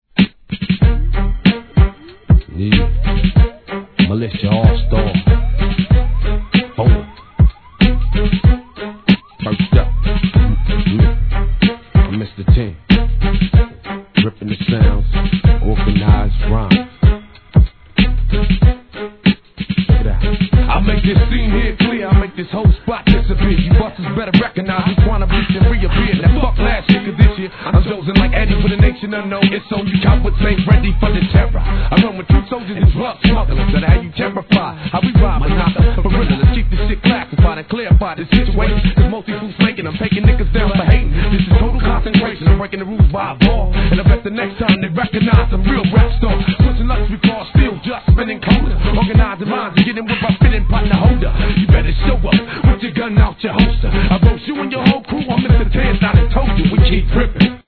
1. HIP HOP/R&B
中盤からのフィメール･ラッパーの絡みもGOOD!!